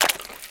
High Quality Footsteps
STEPS Swamp, Walk 03.wav